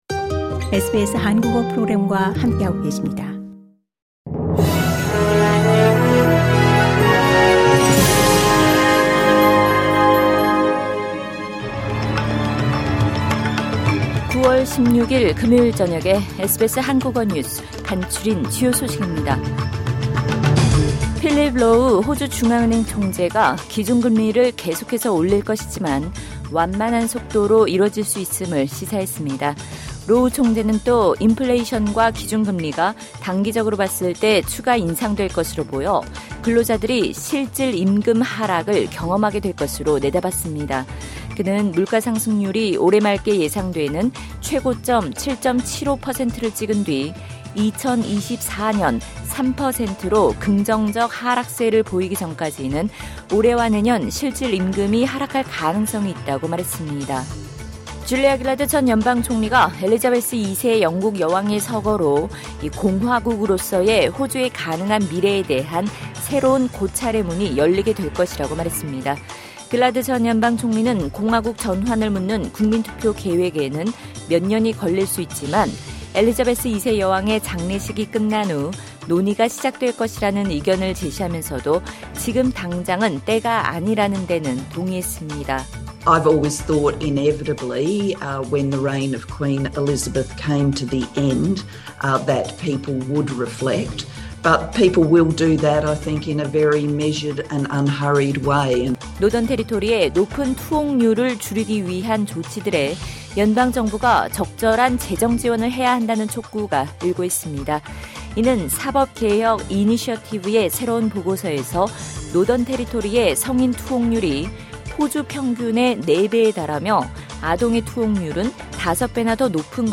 SBS 한국어 저녁 뉴스: 2022년 9월 16일 금요일